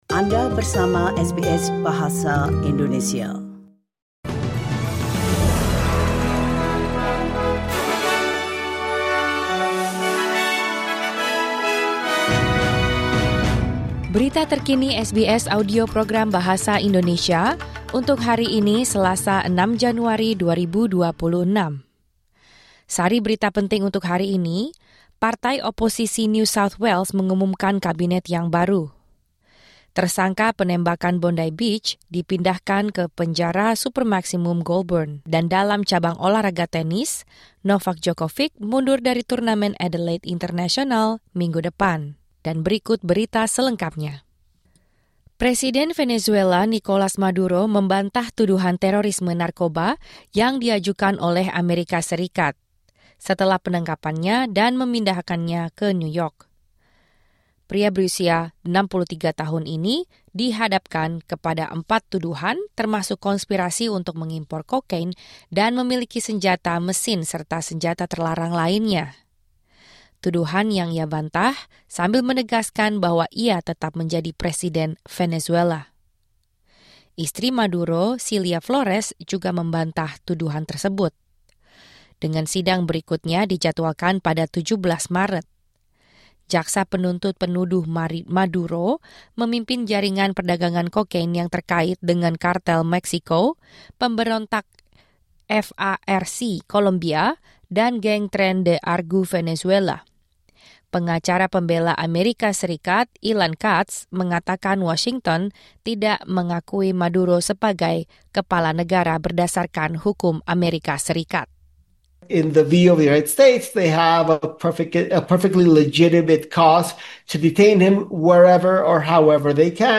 Berita Terkini SBS Audio Program Bahasa Indonesia - Selasa 6 Januari 2026